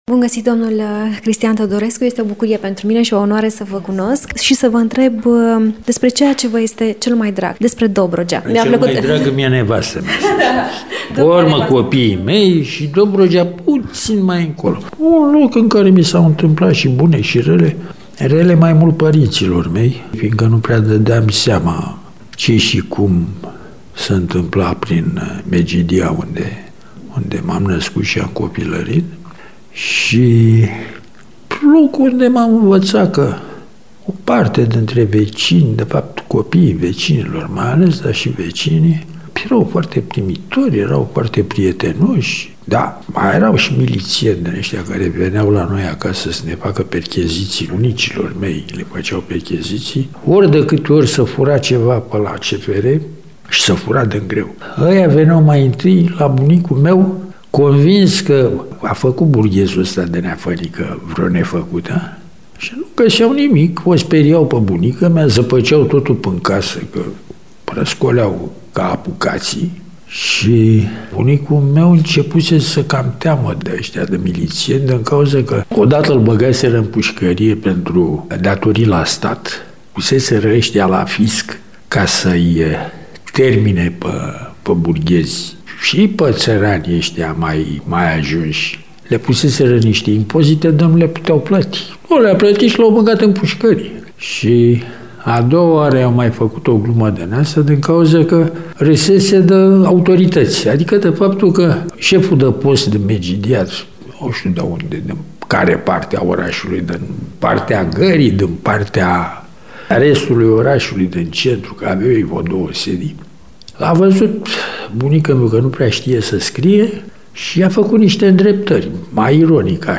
ne spune acesta într-un interviu